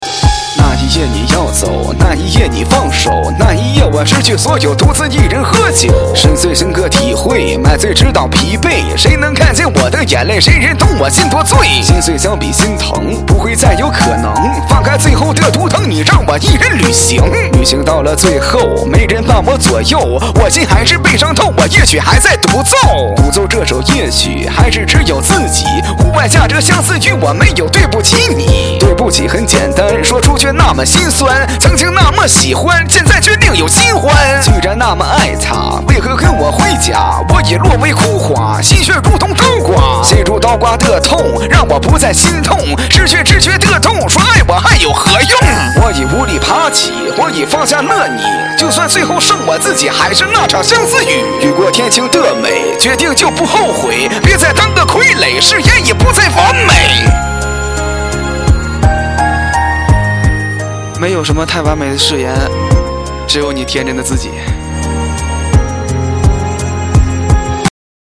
写实情歌